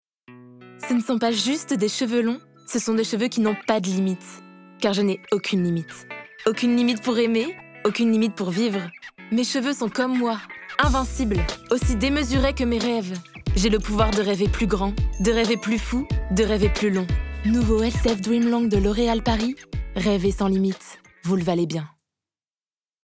Pub - Grain de Malice
- Mezzo-soprano